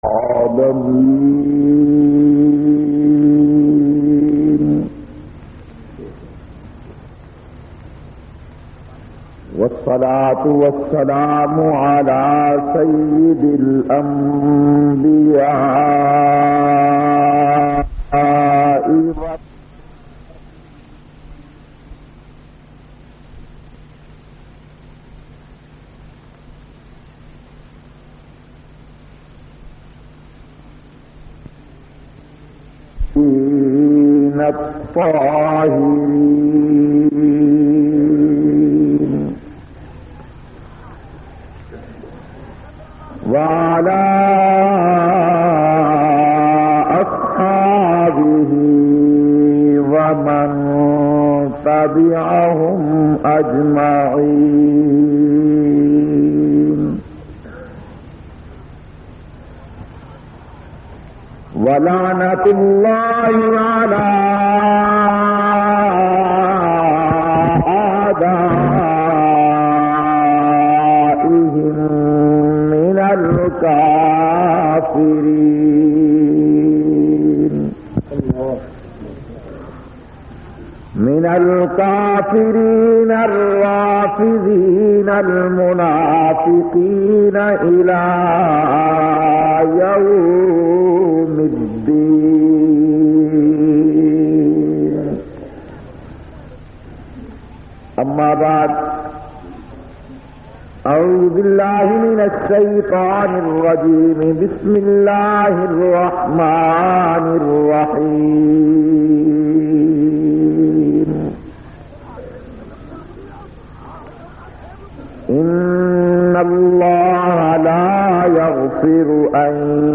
459- Toheed O Sunnat Conference-Jamia Taleem Ul Quran,Raja Bazar, Rawalpindi.mp3